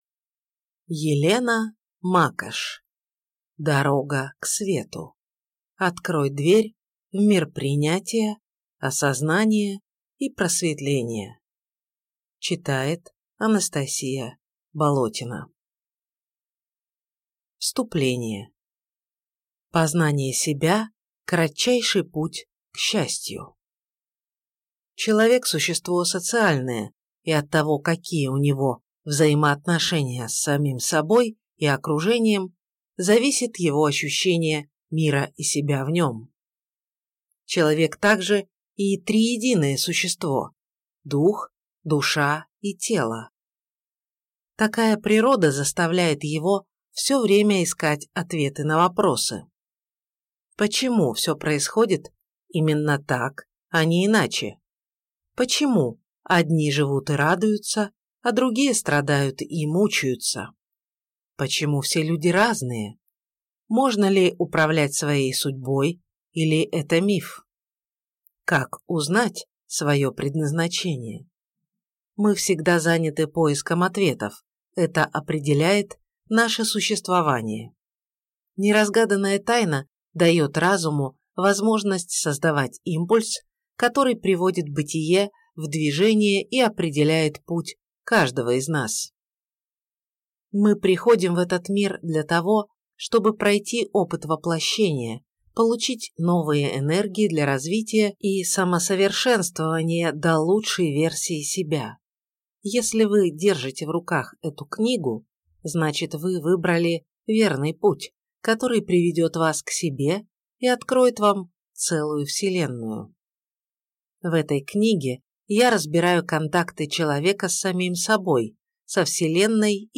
Аудиокнига Дорога к Свету. Открой дверь в мир Осознания, Принятия и Просветления | Библиотека аудиокниг